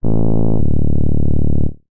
wrong.ogg